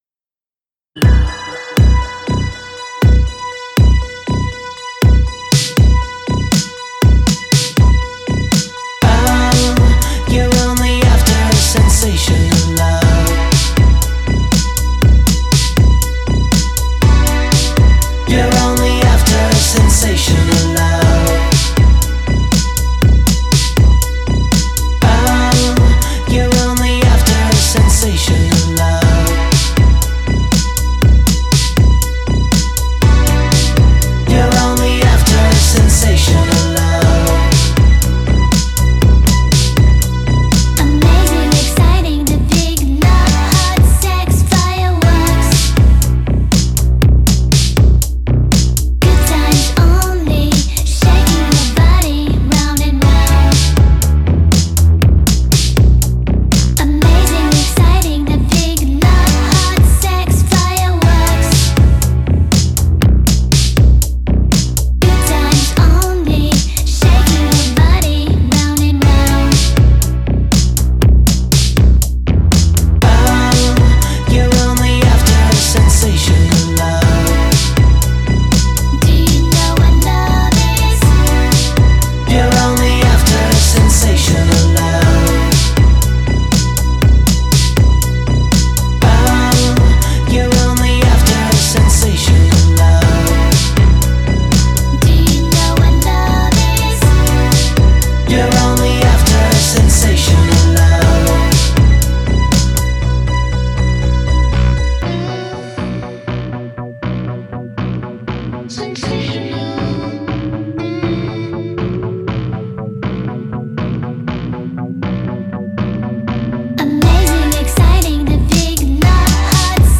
Жанр: Indie Pop
some kind of French Electro-pop